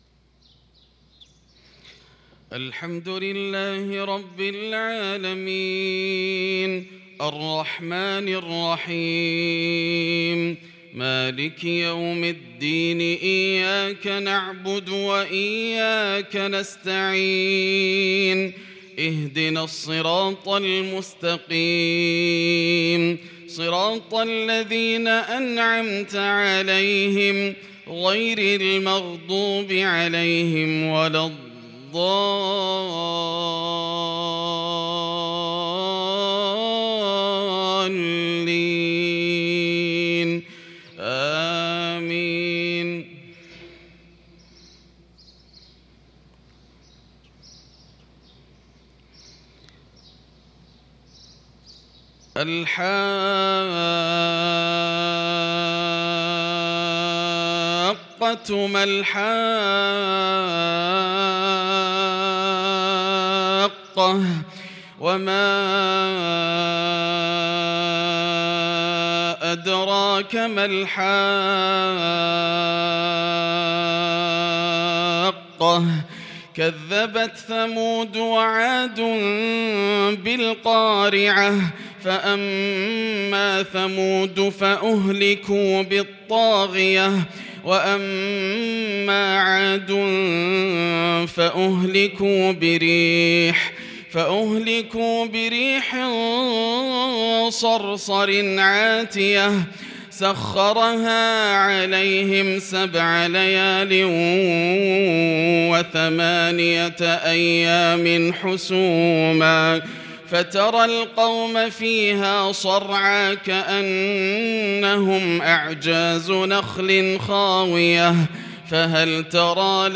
صلاة الفجر للقارئ ياسر الدوسري 7 صفر 1443 هـ
تِلَاوَات الْحَرَمَيْن .